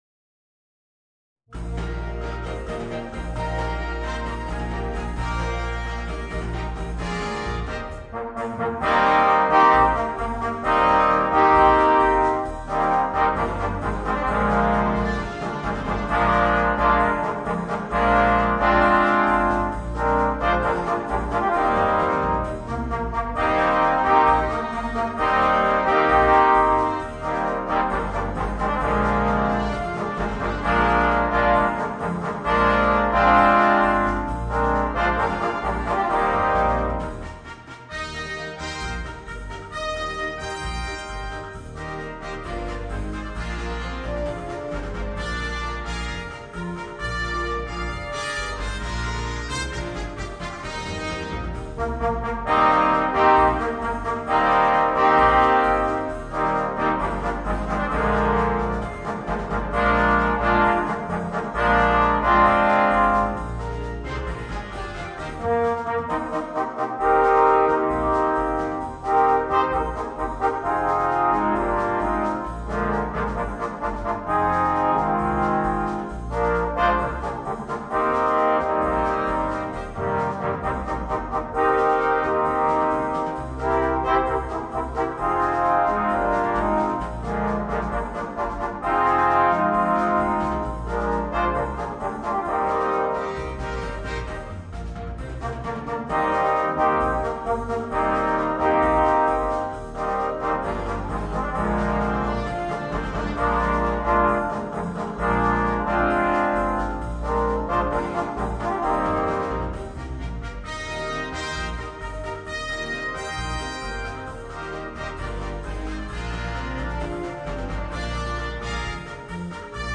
Voicing: 4 Trombones and Concert Band